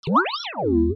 Déplacement 5.mp3